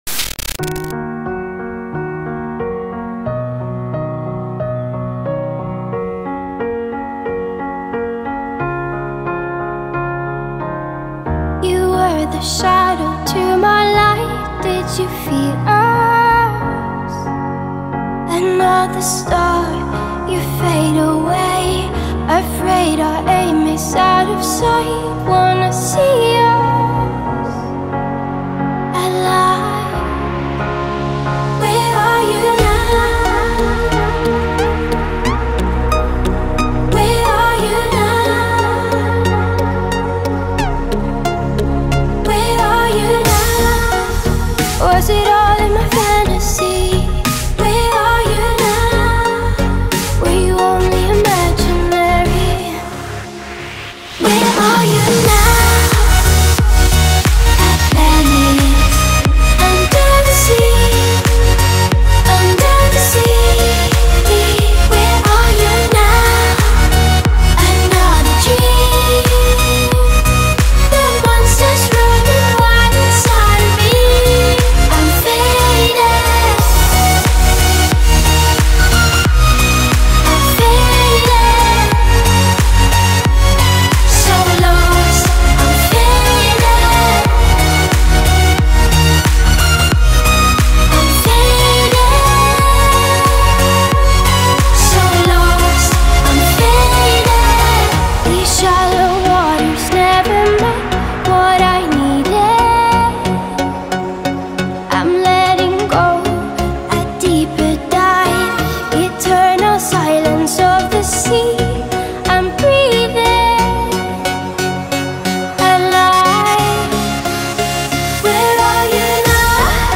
یه ترک الکترونیک با فضای غم‌انگیز، مرموز و درونی